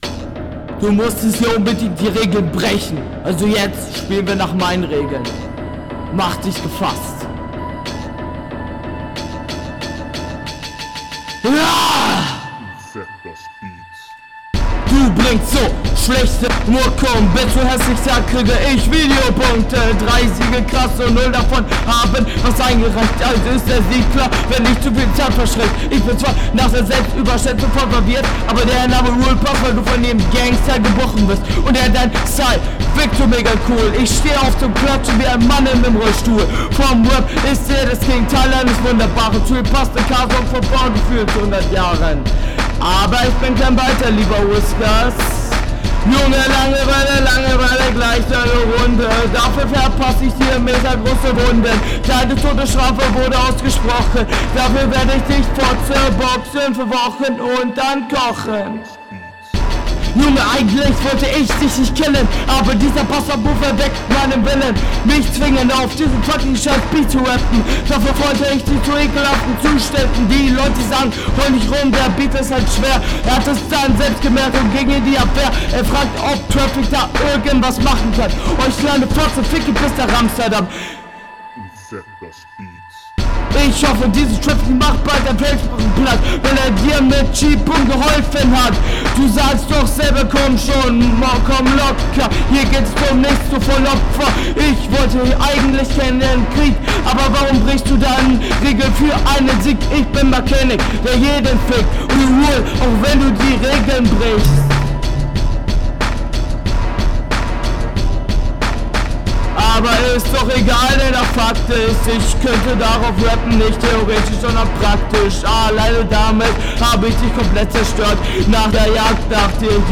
Ja wieder das gleiche Problem mit der Mische, Flow ist echt noch sehr viel unsicherer …